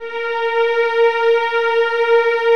VIOLINS CN-L.wav